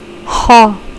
Zöngétlen, ínynél képzett réshangok
x, IITG, egy anyanyelvi beszélő kiejtésében), ami történetileg a szibilánsok egyik fajtájából alakult ki.